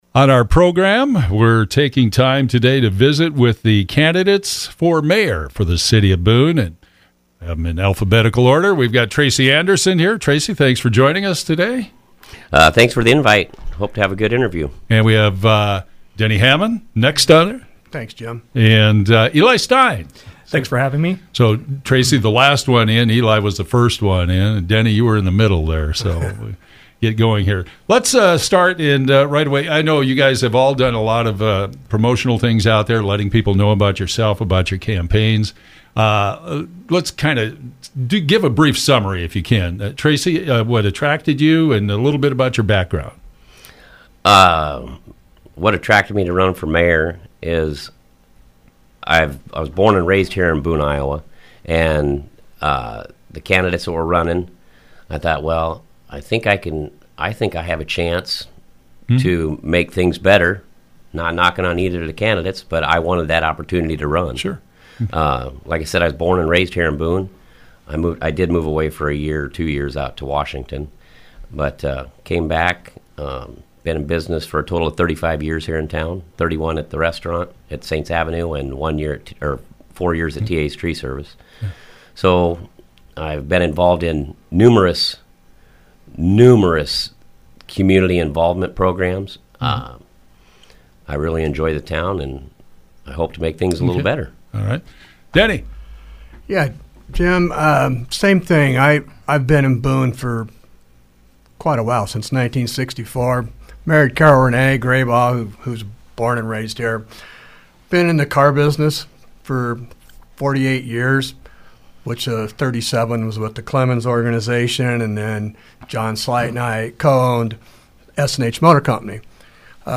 Conversations with Boone Mayor Candidates